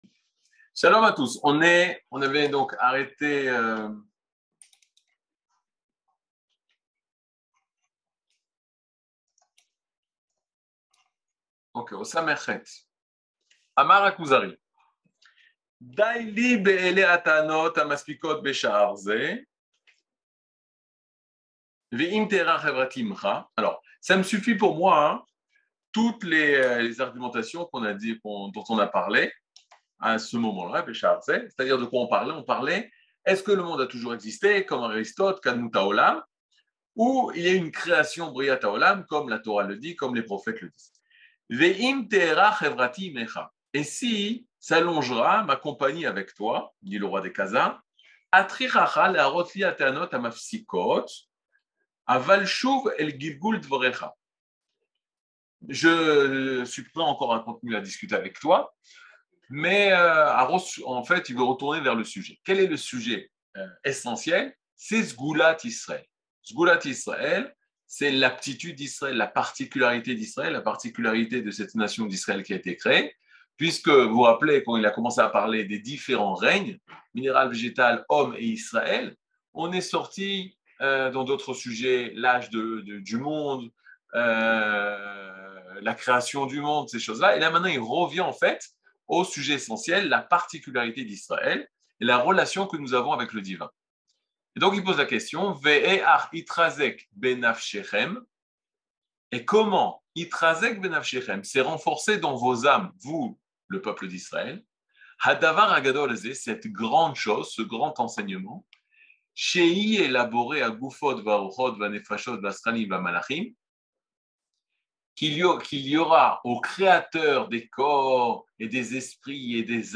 Catégorie Le Kuzari partie 41 00:46:22 Le Kuzari partie 41 cours du 15 mai 2022 46MIN Télécharger AUDIO MP3 (42.45 Mo) Télécharger VIDEO MP4 (89.84 Mo) TAGS : Mini-cours Voir aussi ?